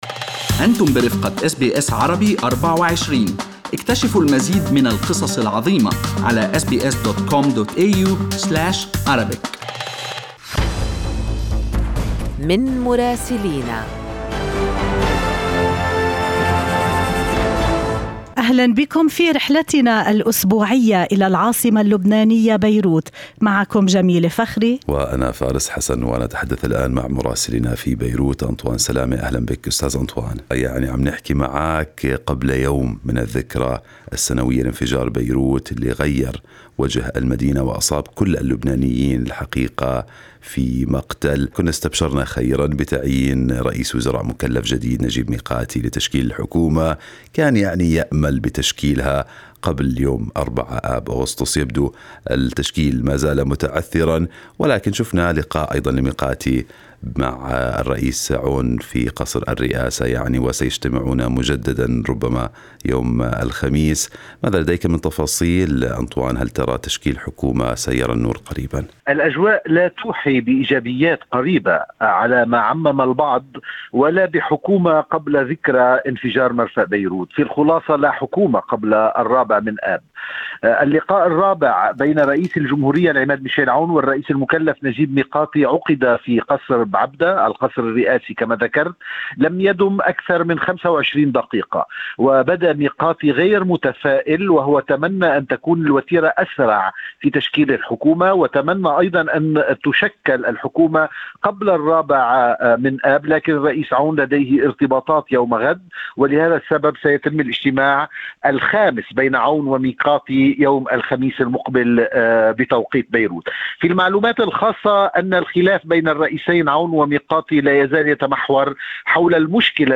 من مراسلينا: أخبار لبنان في أسبوع 3/8/2021